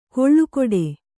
♪ koḷḷu koḍe